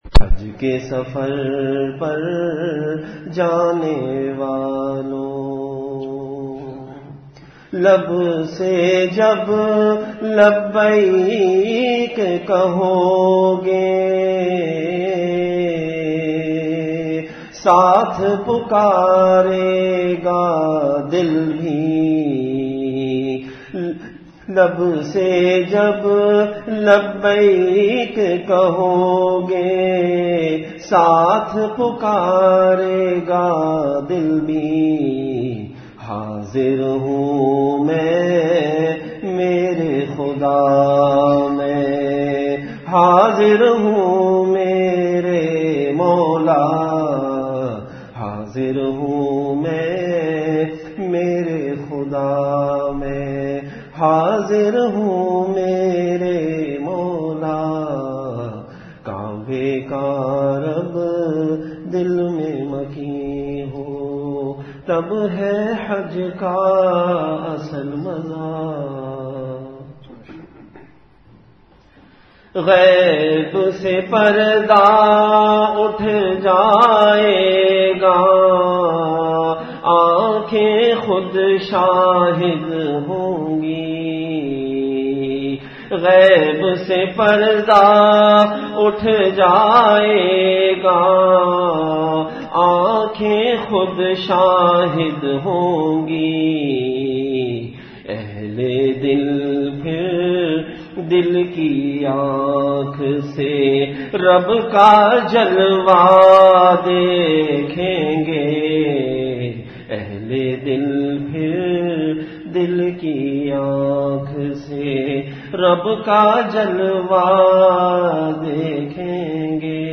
Delivered at Jamia Masjid Bait-ul-Mukkaram, Karachi.
Majlis-e-Zikr · Jamia Masjid Bait-ul-Mukkaram, Karachi